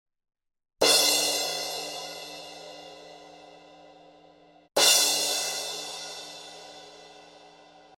Zildjian ZBT 18" Crash/Ride This is a short video to compare two mic placements on a Zildjian ZBT 18" crash/ride cymbal. Placement 1 is above the cymbal facing directly down, slightly towards the edge. Placement 2 is facing the cymbal from the side, towards the bell of the cymbal.
Also, I hope it demonstrates how a cymbal projects the frequencies it can create.